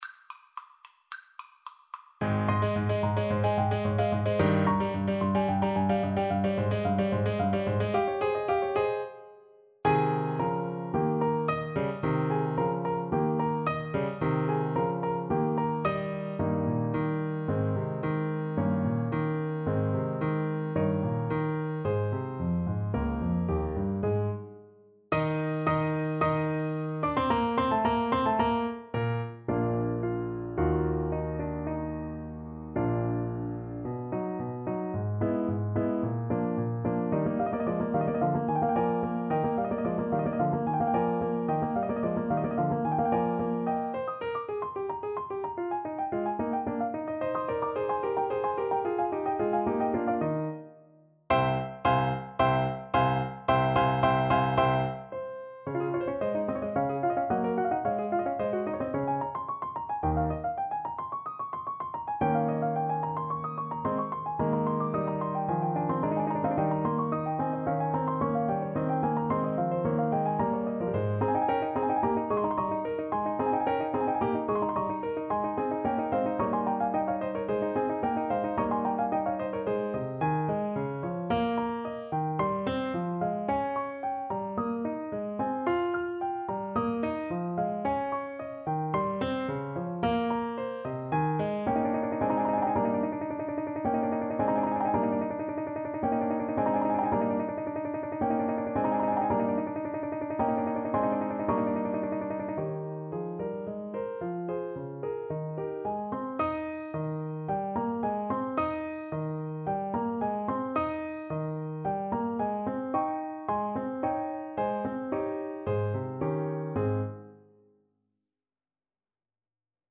Allegro assai =220 (View more music marked Allegro)
Classical (View more Classical Baritone Saxophone Music)